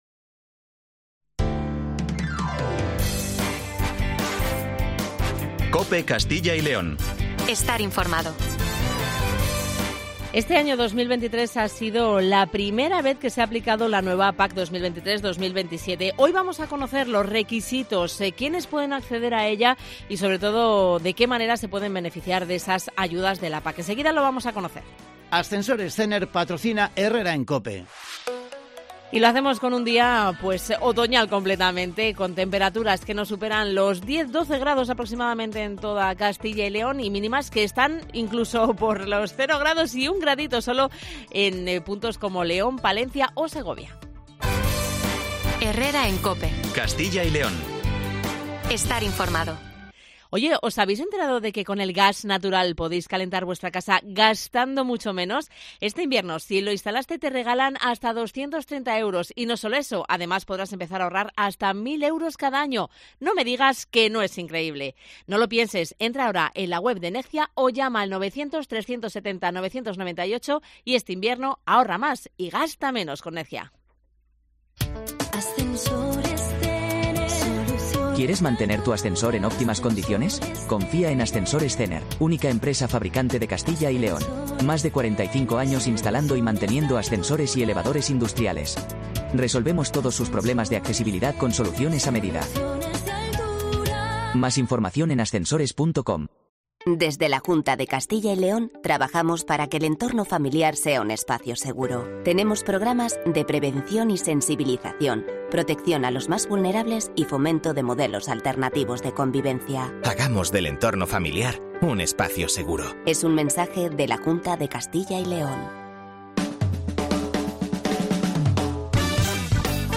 AUDIO: Pedro Medina, viceconsejero de Agricultura, Ganadería y Desarrollo Rural nos explica los requisitos para acceder a la PAC y quiénes pueden...